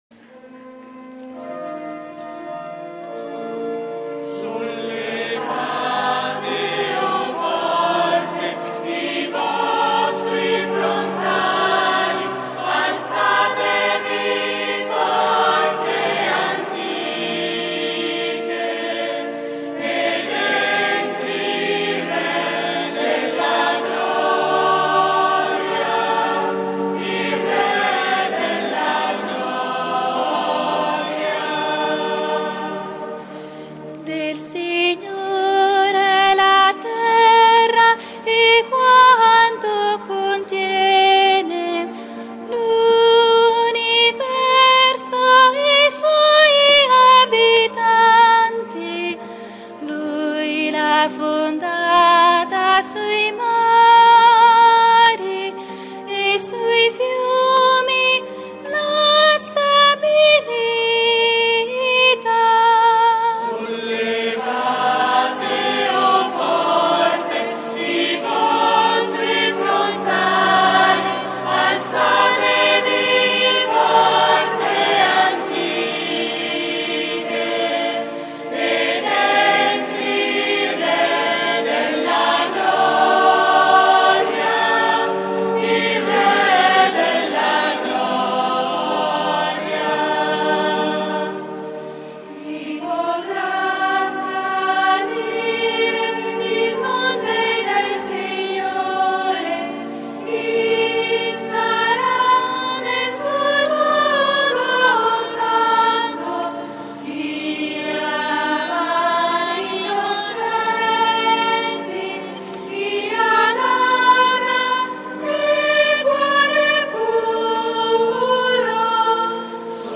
DOMENICA DELLE PALME
canto: